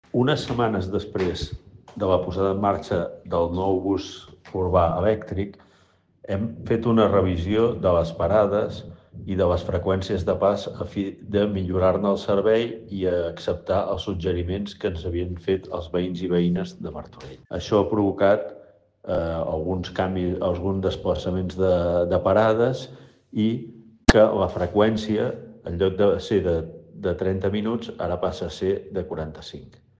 Lluís Sagarra, regidor de Mobilitat